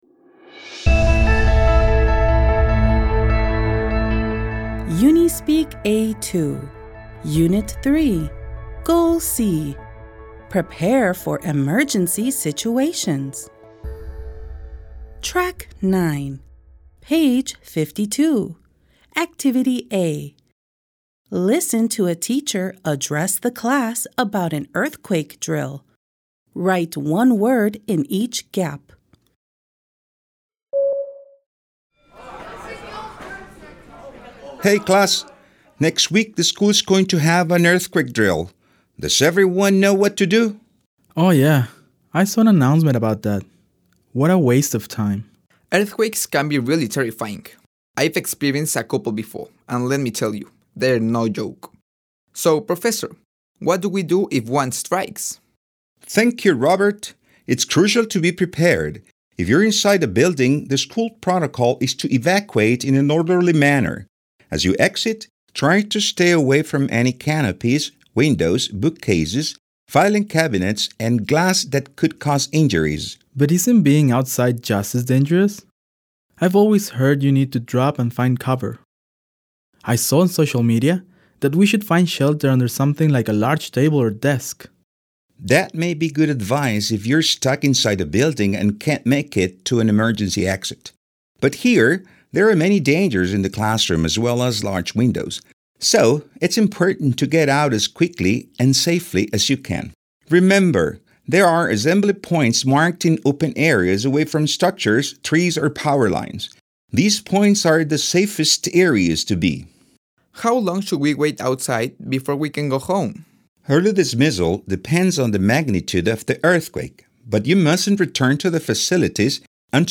Part 1 Unidad 3 : My Hometown Objetivo C : Prepare for Emergency Situations Ejercicio A, página 52. Listen to a teacher address the class about an earthquake drill.